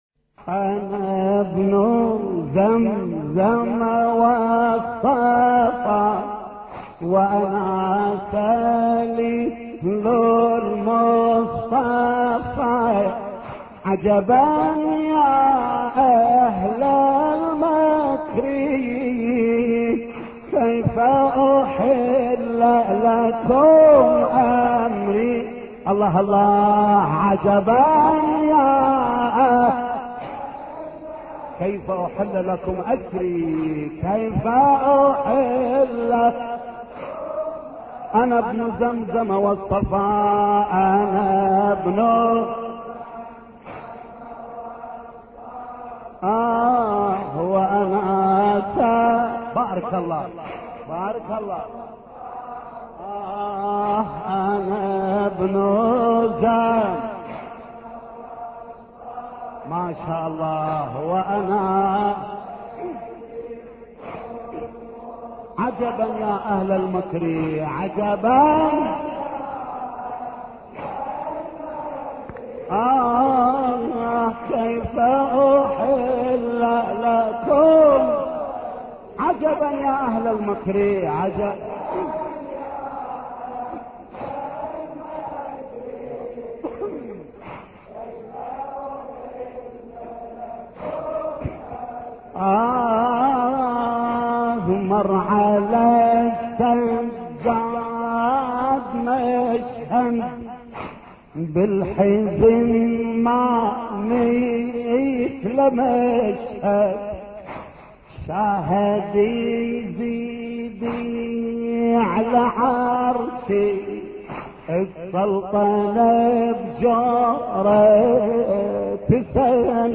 مراثي الامام السجاد (ع)